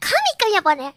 Worms speechbanks